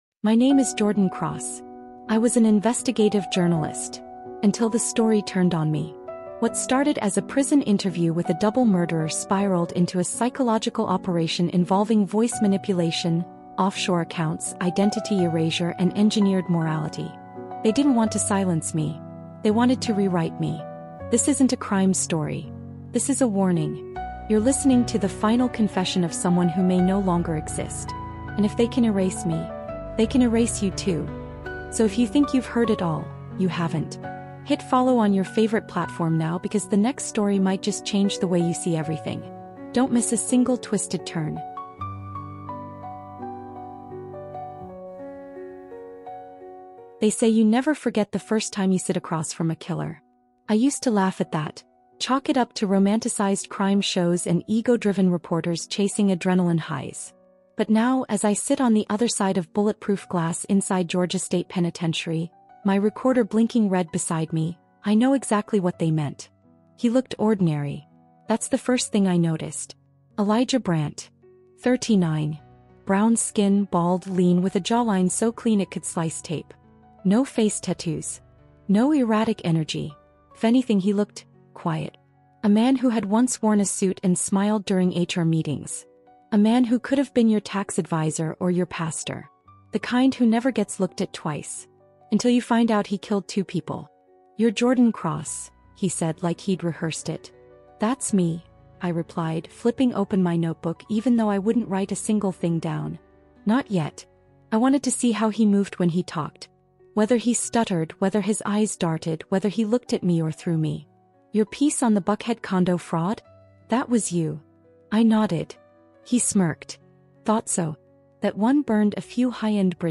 The Shocking Confession of a Double Murderer is a gripping true crime audiobook thriller that dives deep into the psychology of manipulation, persuasion, and control.